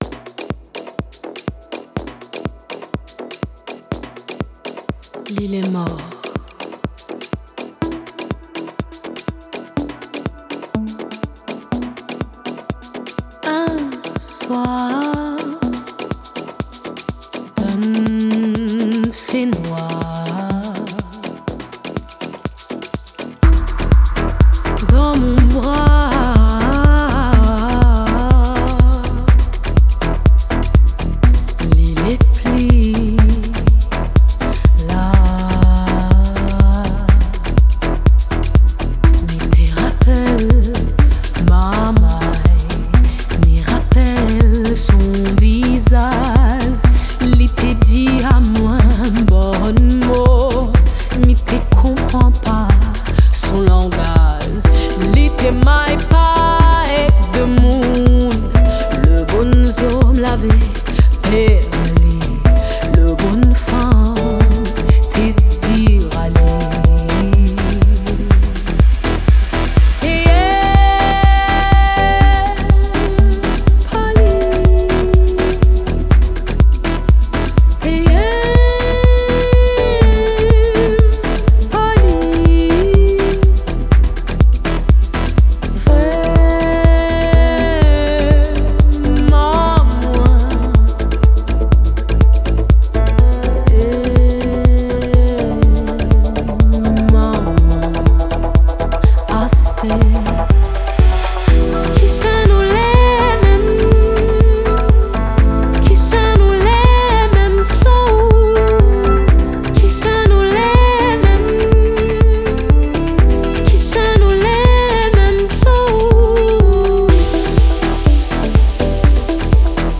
blending Creole vocals and electric sounds.